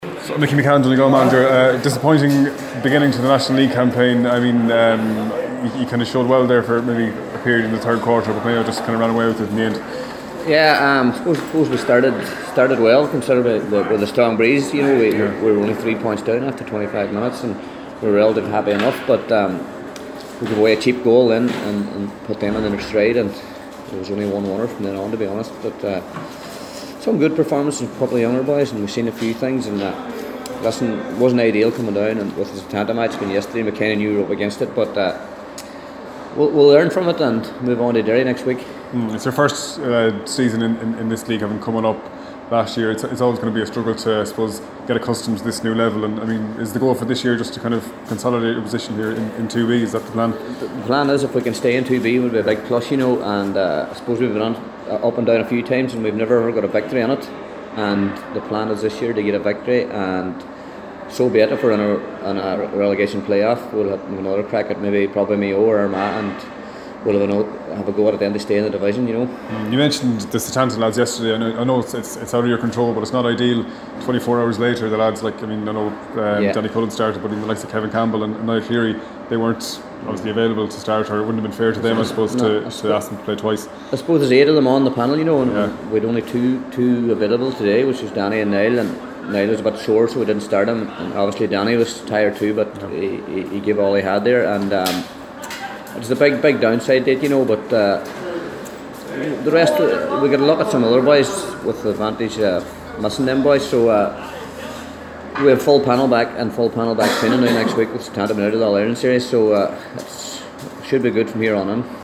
After the game,